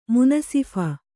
♪ munasīph kōrṭu